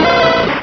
Cri de Caninos dans Pokémon Rubis et Saphir.